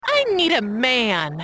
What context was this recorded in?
All right so the mystery mod sounds are from a pinball game called Monster Bash.